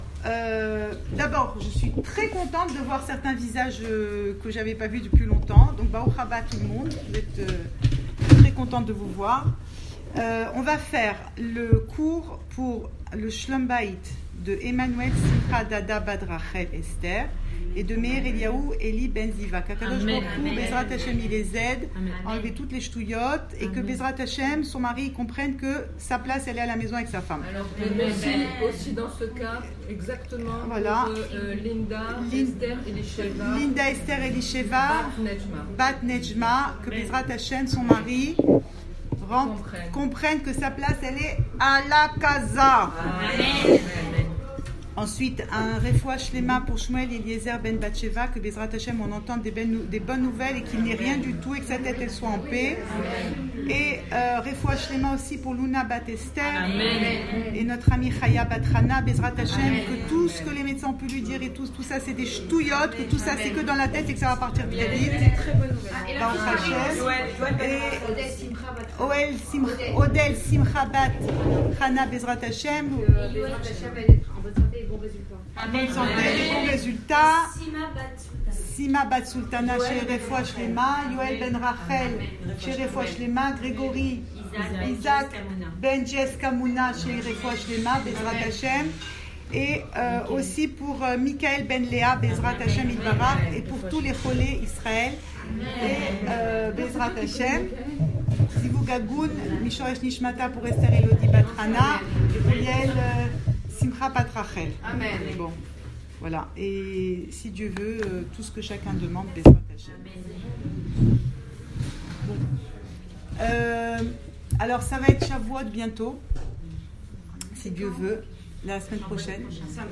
Cours audio Fêtes Le coin des femmes - 8 mai 2018 9 mai 2018 Chavouot, la plus belle des Houppot ! Enregistré à Raanana